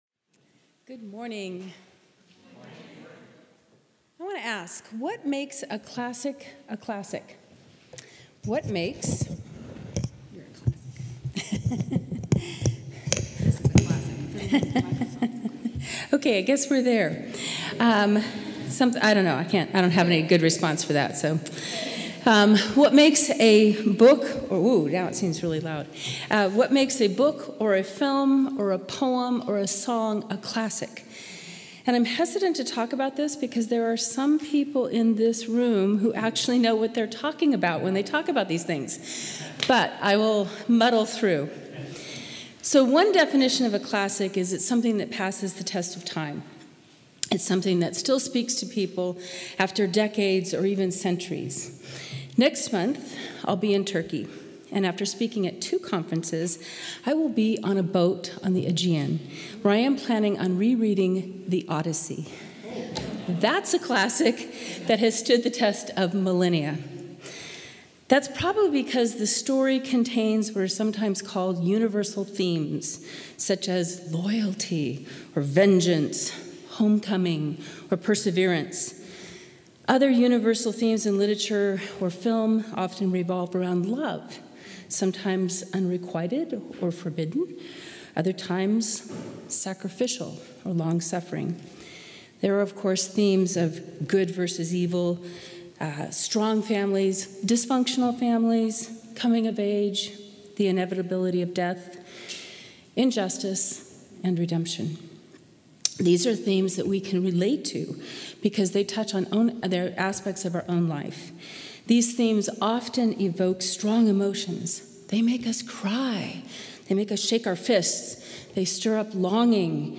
1 COR Sermon – May 18, 2025 30:48